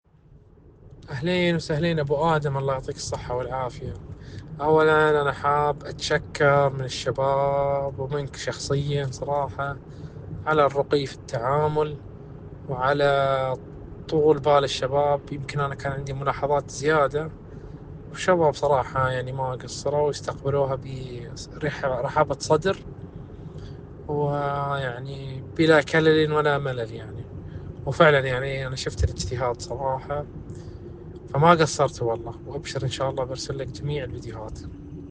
رأي-العميل-في-إشراقات-للنظافة.mp3